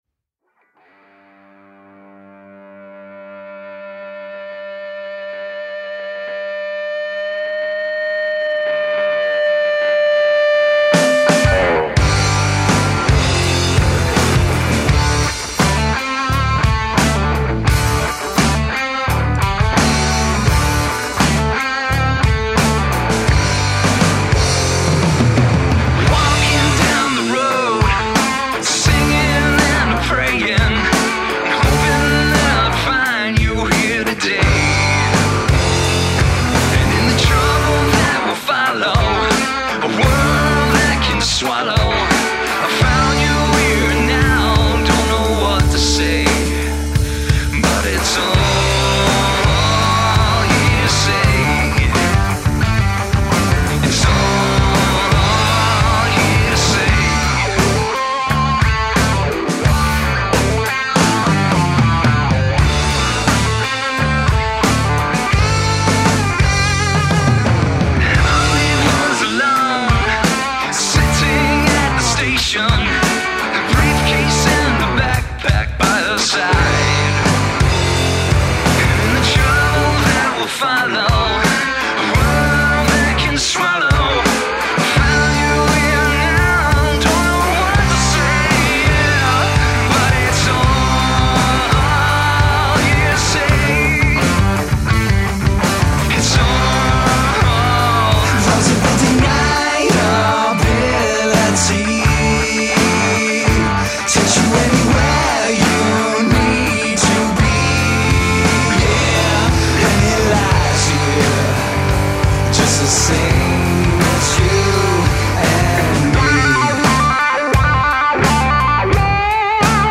Rock, Blues Rock, Alternative Rock